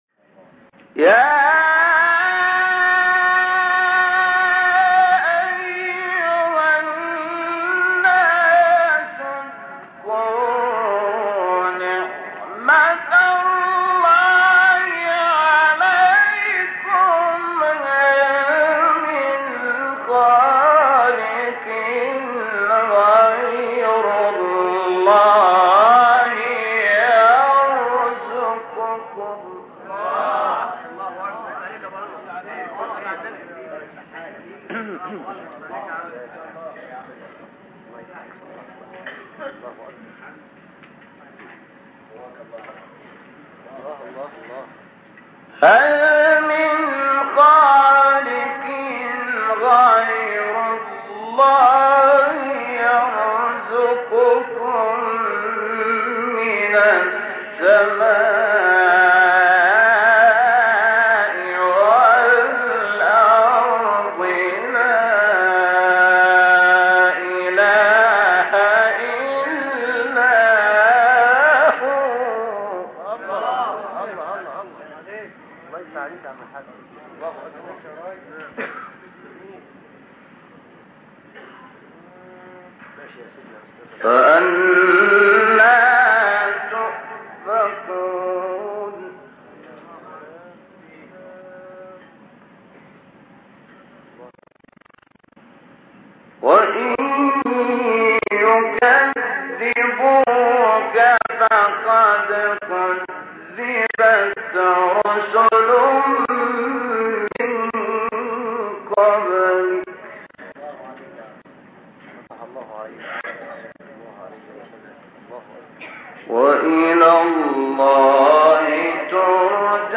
تلاوت آیه 3-6 سوره فاطر استاد شحات | نغمات قرآن | دانلود تلاوت قرآن